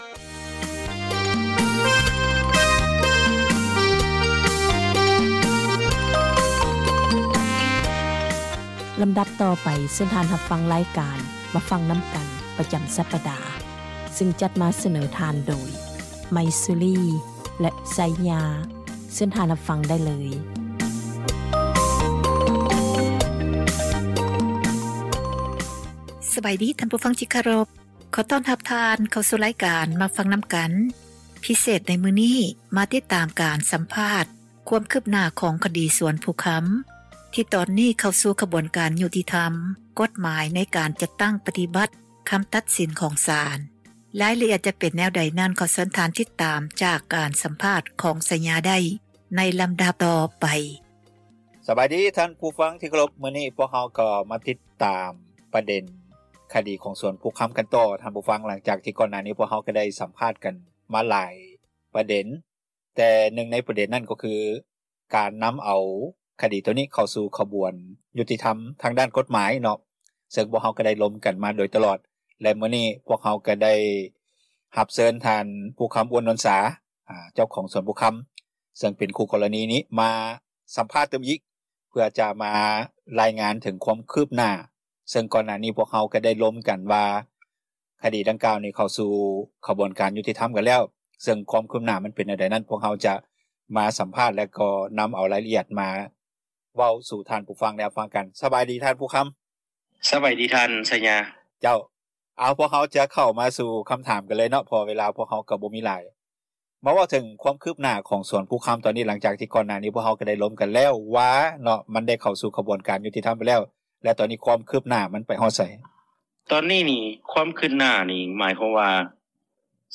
ຕິດຕາມການສຳພາດ ຄວາມຄືບໜ້າ